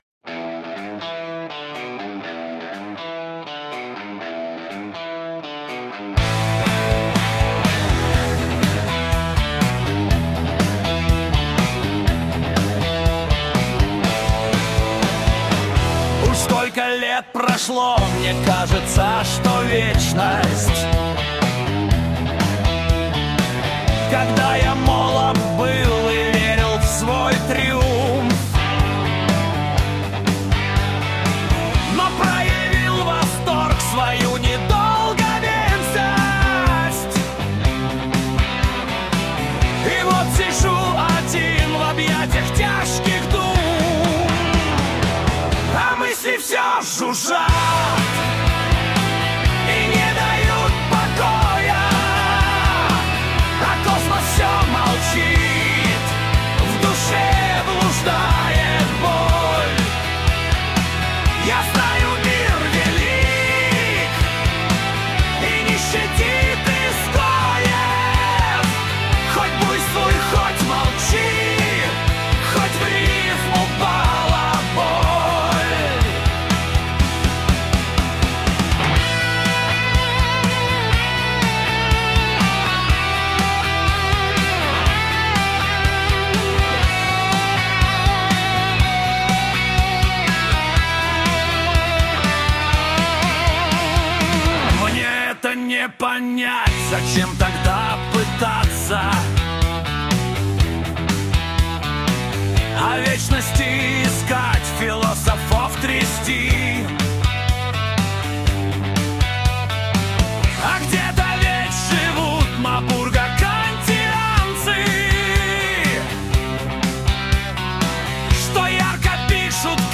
Русский рок".mp3